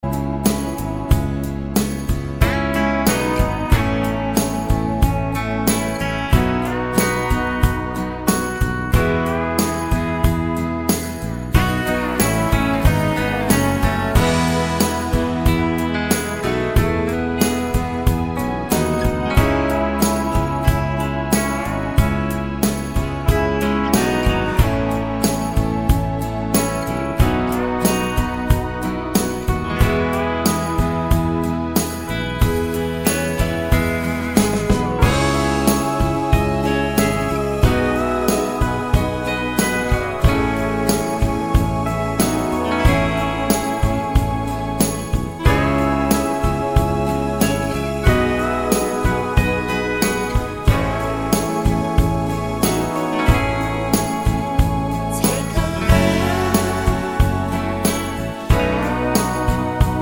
no Backing Vocals Soft Rock 4:38 Buy £1.50